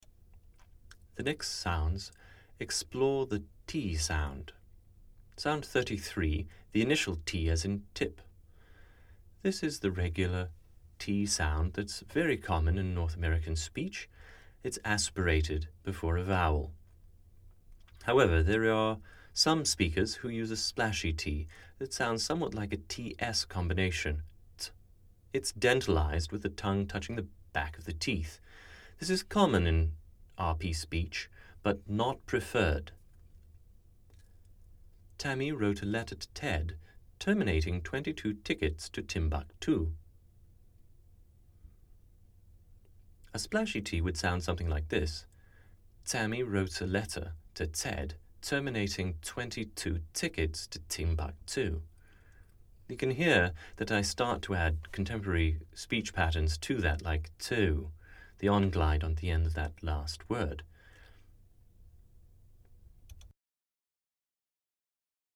Received Pronunciation (RP)
Initial T